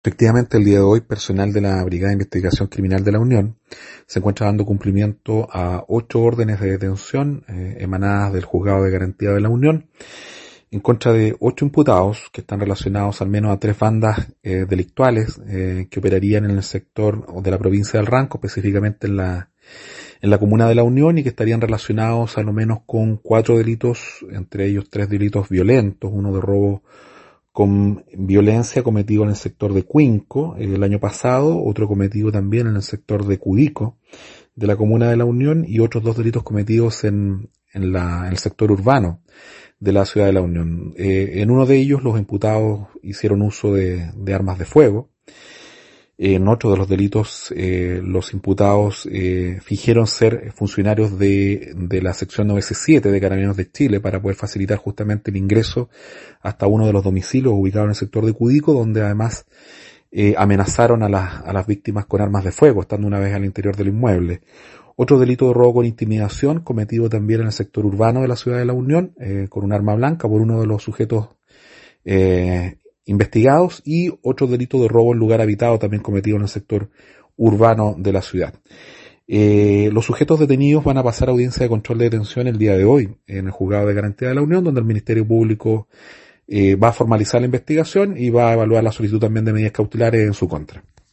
Expreso esta tarde el fiscal Alex Montesinos Cárdenas